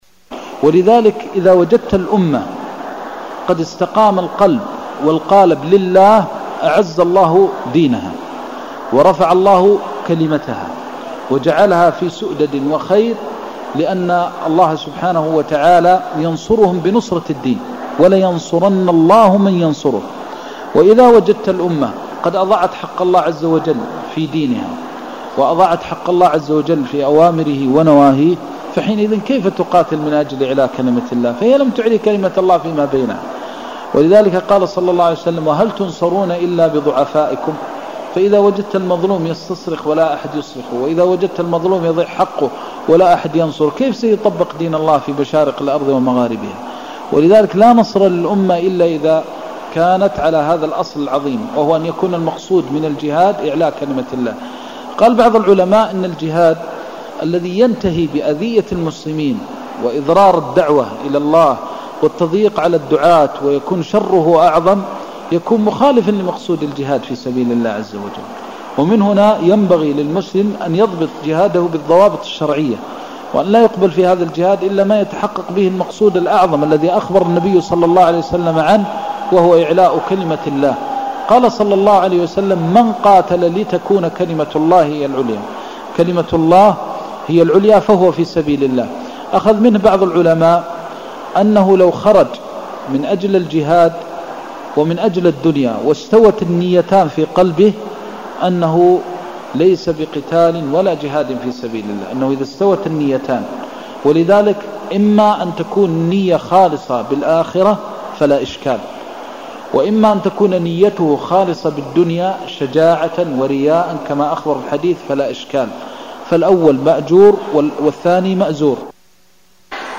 المكان: المسجد النبوي الشيخ: فضيلة الشيخ د. محمد بن محمد المختار فضيلة الشيخ د. محمد بن محمد المختار الجهاد (04)